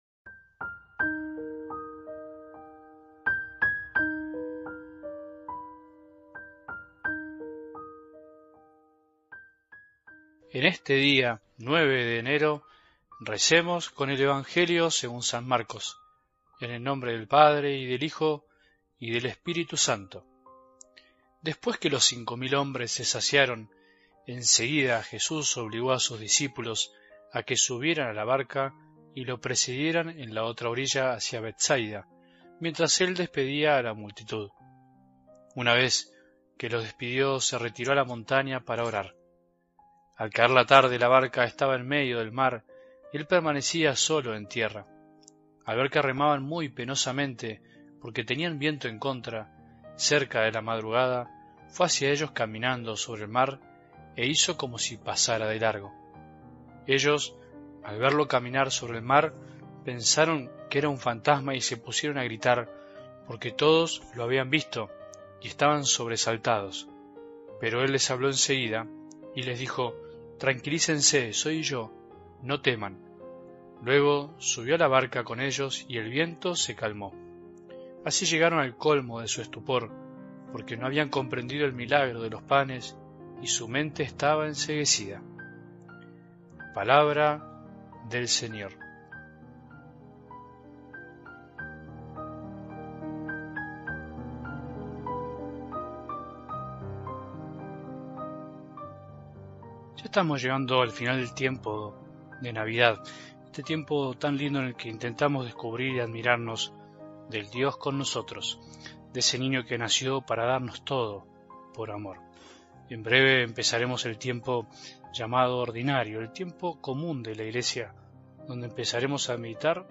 Oración del 9 de Enero de 2025
Reflexión